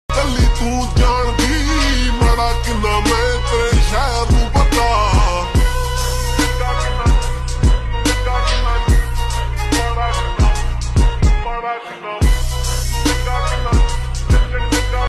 SLOWED+REWERB 🎧 SONG